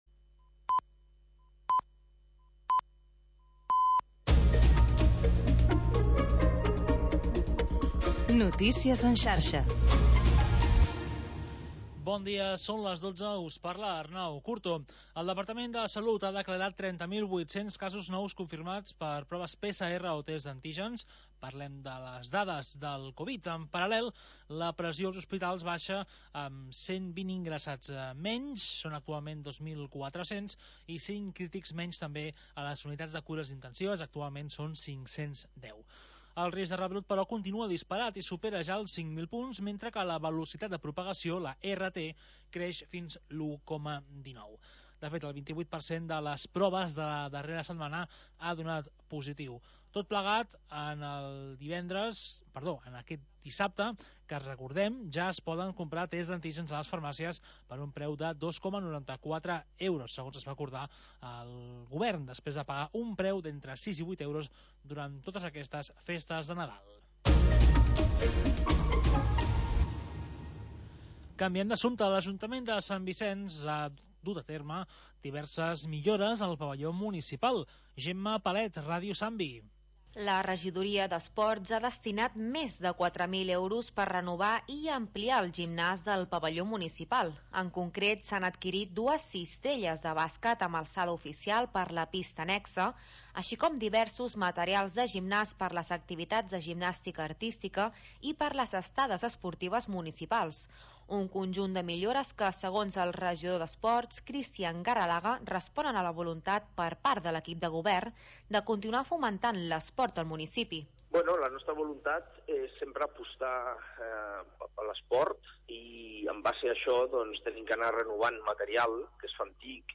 Programa sardanista